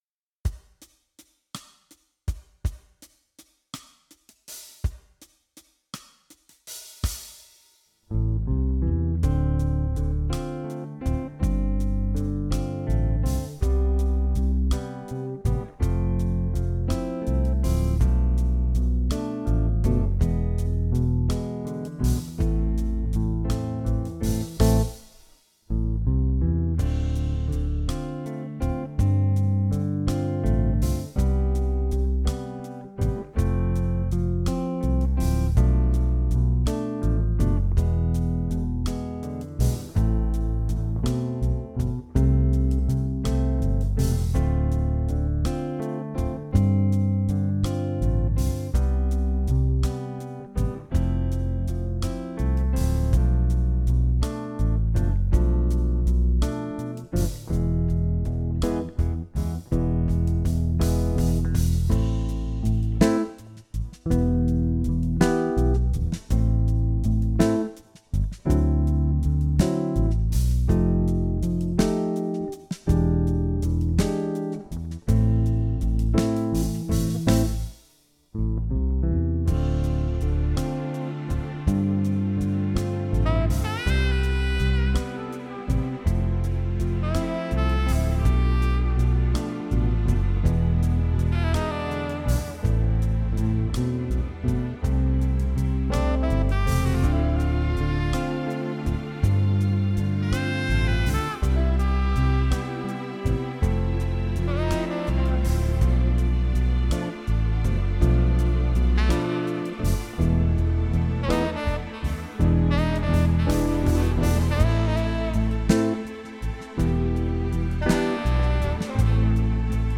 Jam Track
Jam track